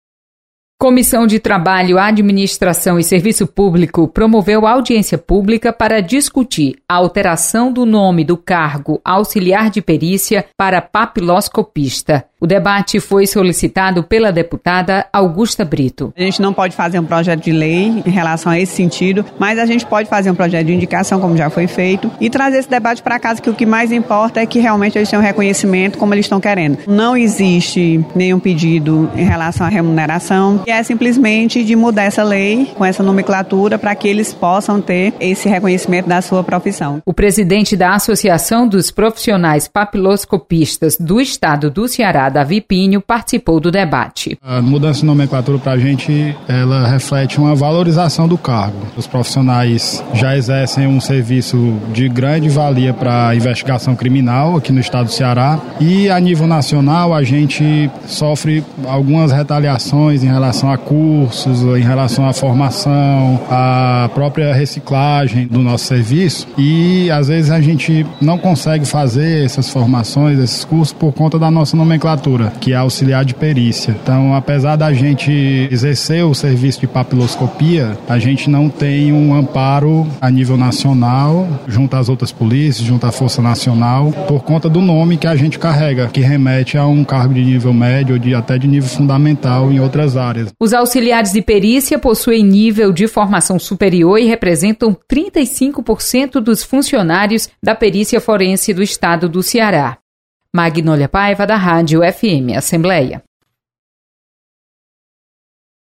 Comissão debate mudança na nomenclatura de auxiliar de perícia. Repórter